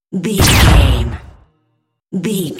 Dramatic stab laser shot deep
Sound Effects
Atonal
heavy
intense
dark
aggressive
hits